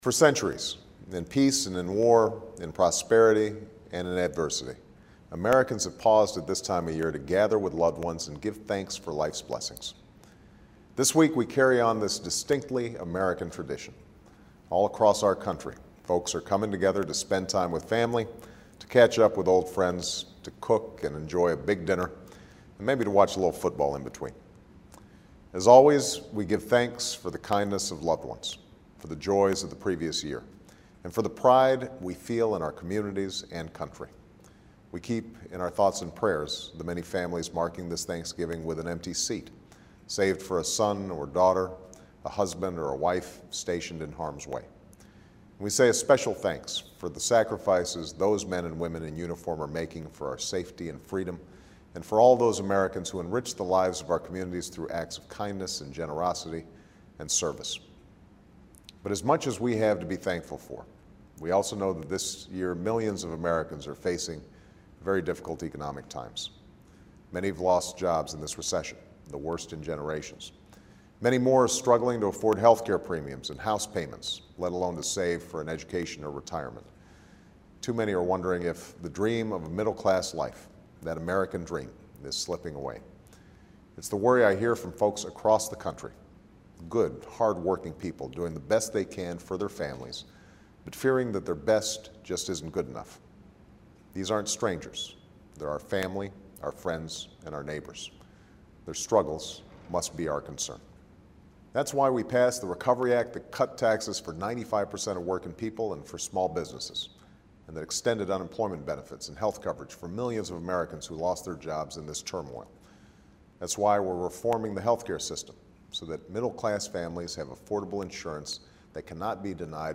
Weekly Address: President Obama Delivers Thanksgiving Greeting
Weekly Address